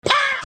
PLAY wah sound effect waluigi
waaah_M9xGvmG.mp3